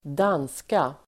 Uttal: [²d'an:ska]